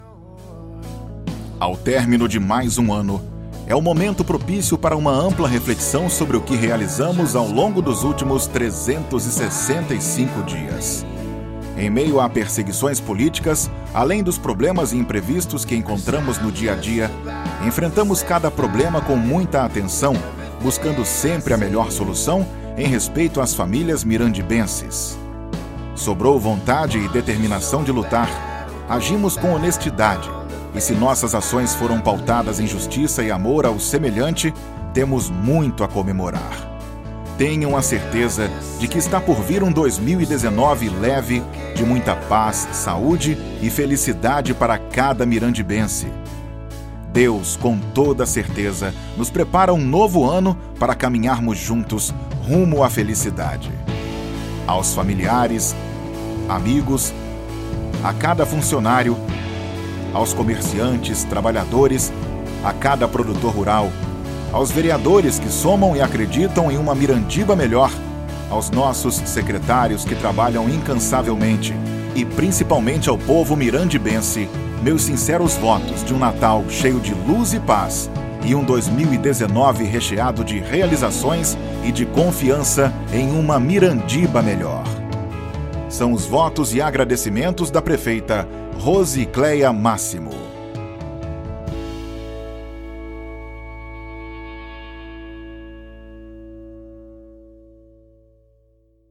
Mirandiba: Prefeita Rose Cléa emite mensagem de natal aos mirandibenses
Mensagem-de-natal-Rose-Clea-2018.mp3